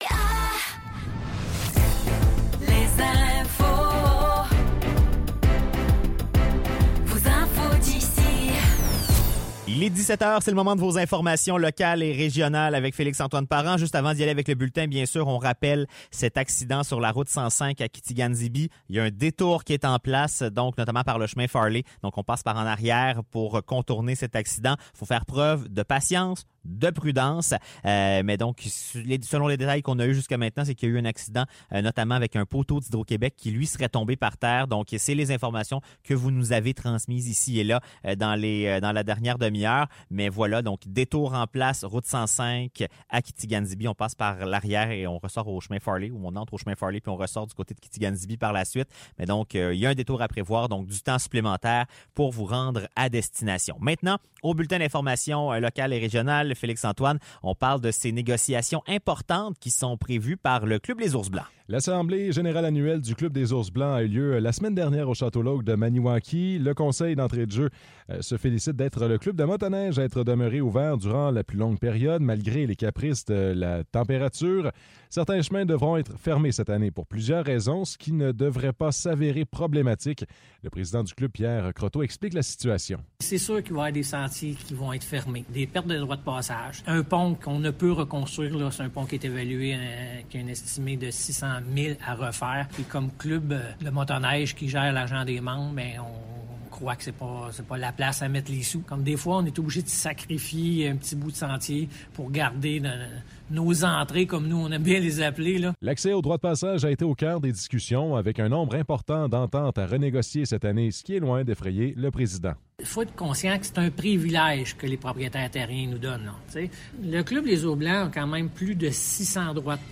Nouvelles locales - 21 novembre 2024 - 17 h
CHGA FM vous informe tout au long de la journée. Retrouvez les nouvelles locales du jeudi 21 novembre 2024 de 17 h.